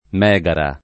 vai all'elenco alfabetico delle voci ingrandisci il carattere 100% rimpicciolisci il carattere stampa invia tramite posta elettronica codividi su Facebook mégaron / μέγαρον [gr. ant. m $g aron ] s. m. (in it.) archeol.; pl. mégara / μέγαρα [ m $g ara ]